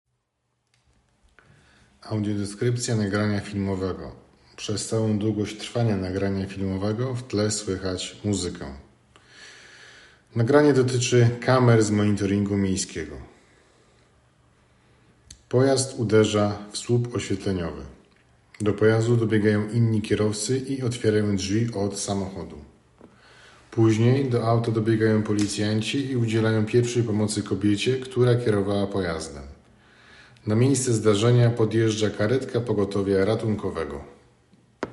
Audiodeskrypcja filmu - plik mp3